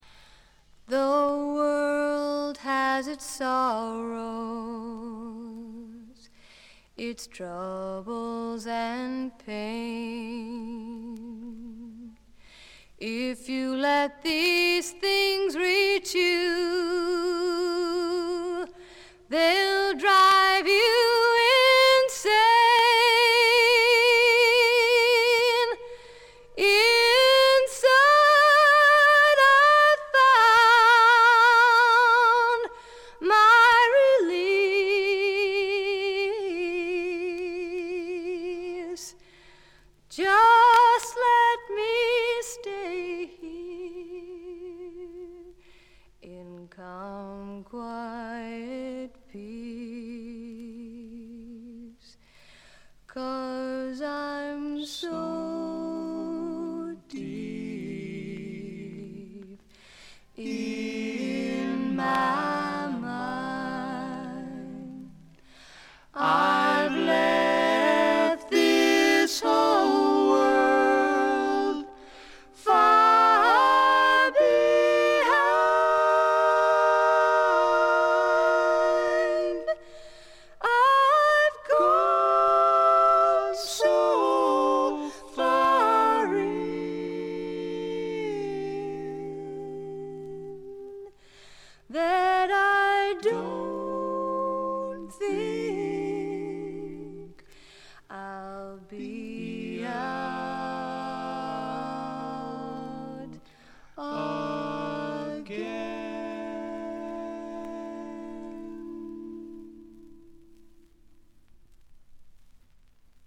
ホーム > レコード：英国 SSW / フォークロック
軽微なチリプチ少々。
静と動の対比も見事でフォークロック好きにとってはこたえられない作品に仕上がっています！
試聴曲は現品からの取り込み音源です。